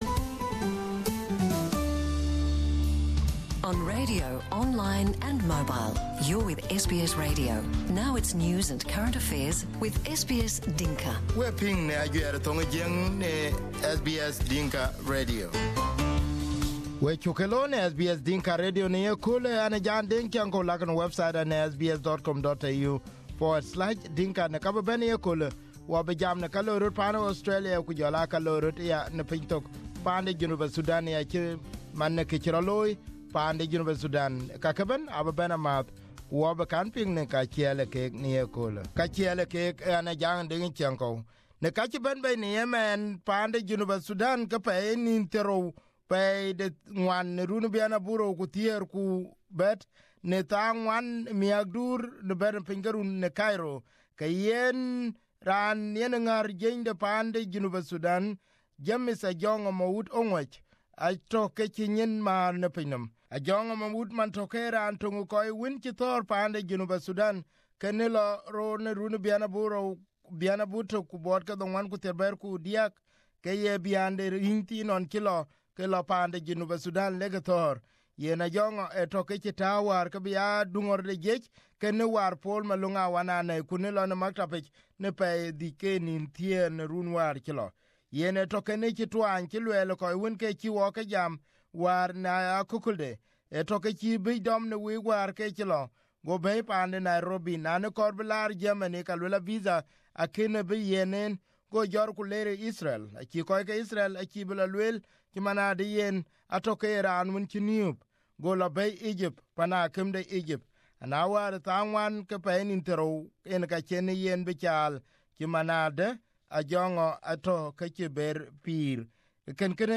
Dr. Majak was interviewed earlier by VOA and again on a Saturday program at 11 am on SBS Dinka Radio.